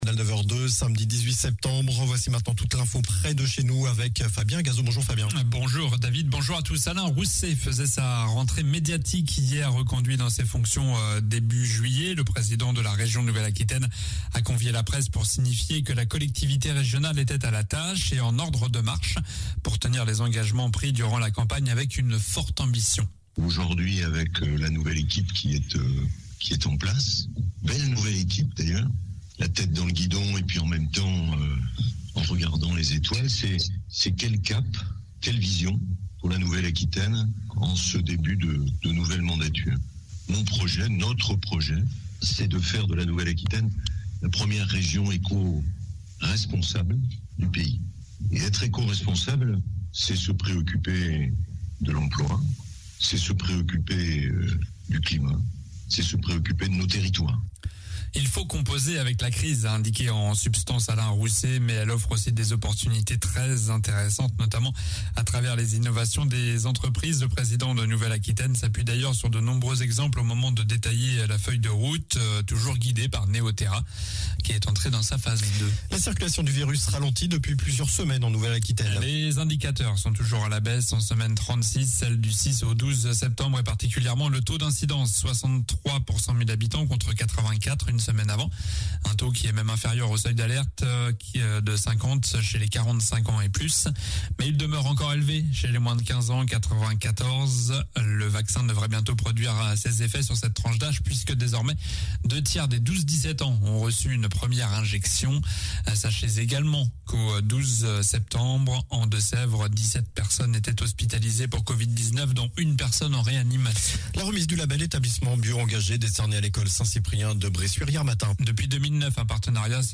Journal du samedi 18 septembre (matin)